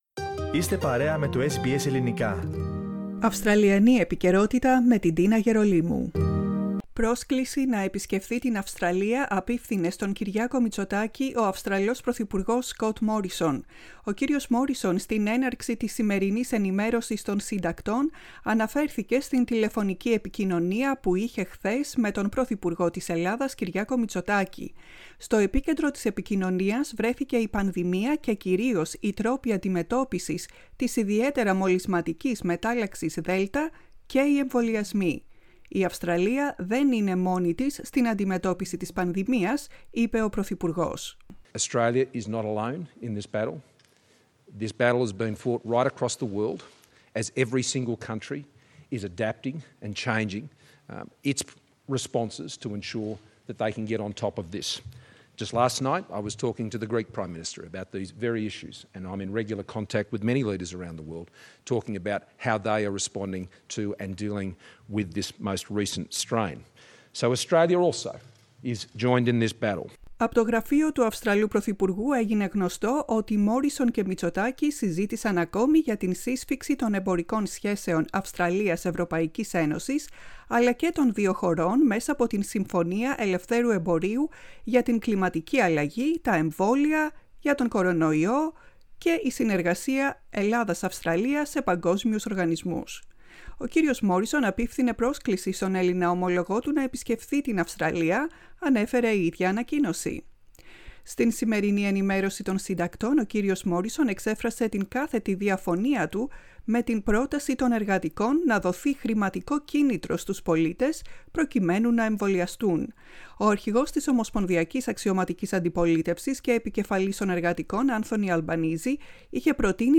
Prime Minister Scott Morrison speaks to the media during a press conference at Parliament House in Canberra.